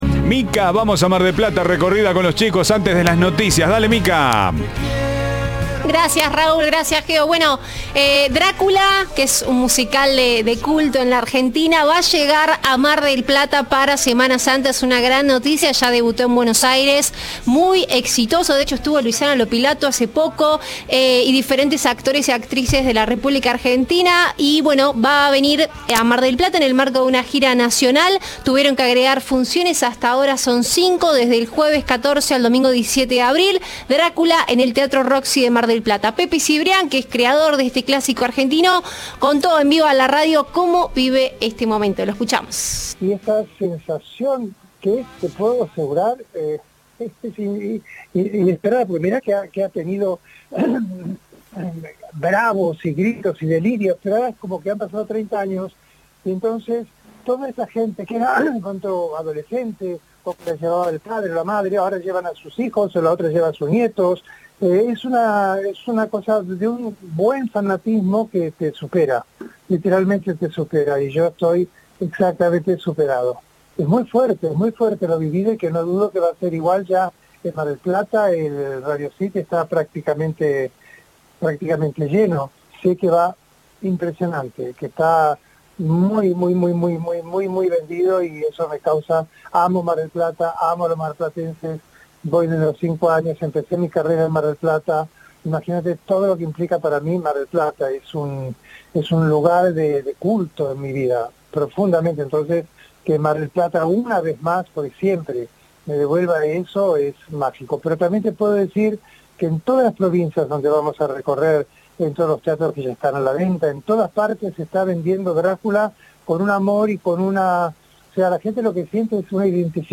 “Mueve un fanatismo que supera”, dijo a Cadena 3 su creador, Pepe Cibrián.